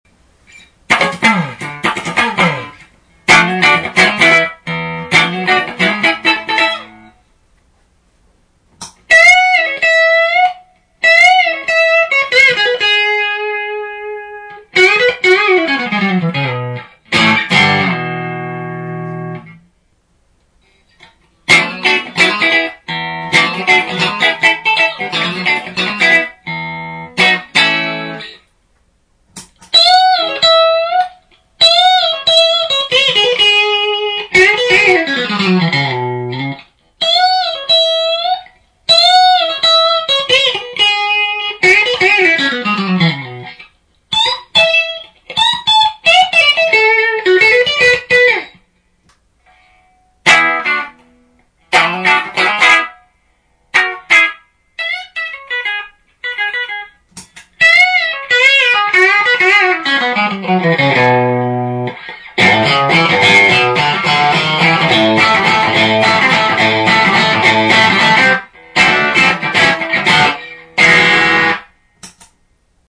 ギターからモガミのシールドケーブル(5m・スイッチプラグ付)→Carl MartinのHot Drive'n Boost→Whirlwindのシールドケーブル(1m)→Two-Rock Topazの順です。
で、このセッティングでもう一度弾いてみました。
ううむ、少しマシですかねえ？